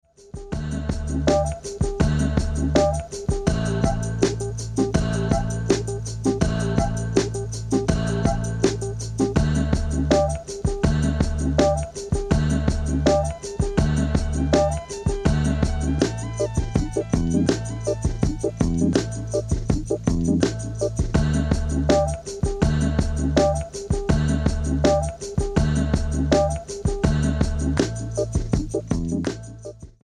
turning it into a spaced out slice of cinematic funk